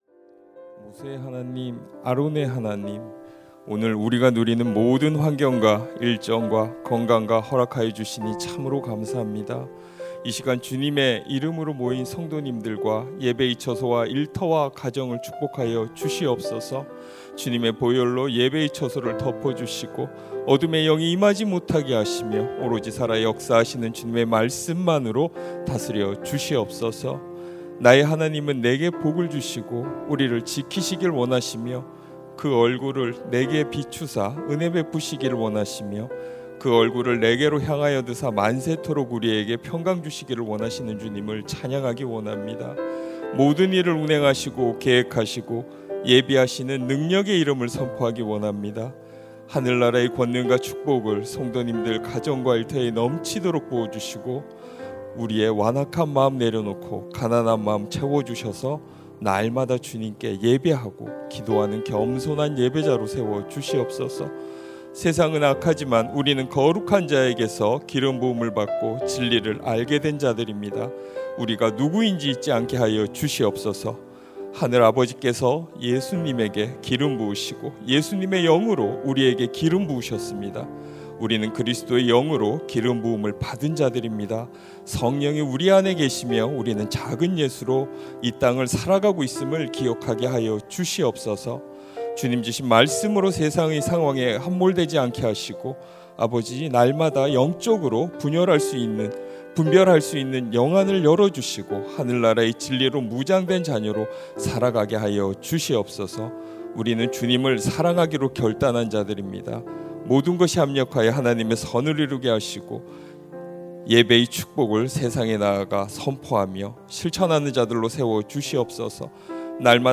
2025-10-21 새벽기도회
[새벽예배]